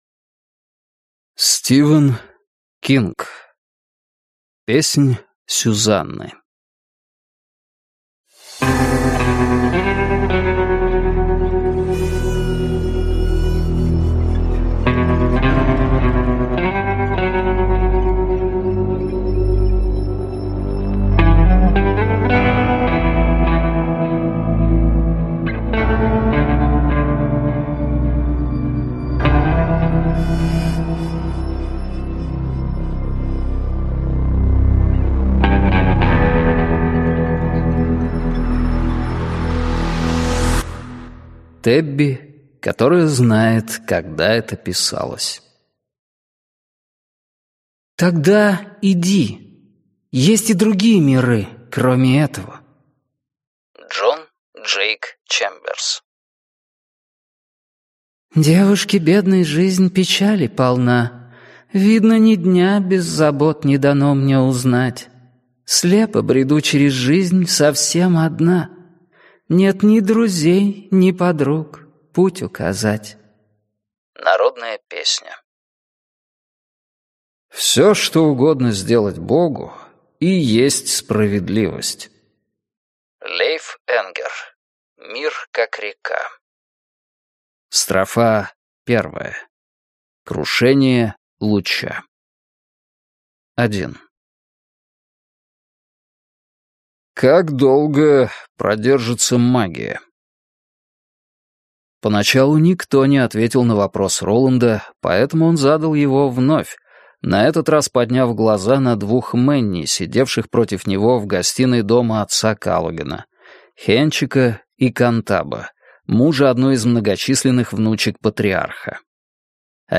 Аудиокнига Песнь Сюзанны - купить, скачать и слушать онлайн | КнигоПоиск